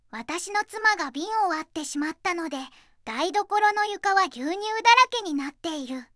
voicevox-voice-corpus
voicevox-voice-corpus / ita-corpus /ずんだもん_ノーマル /EMOTION100_050.wav